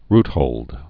(rthōld, rt-)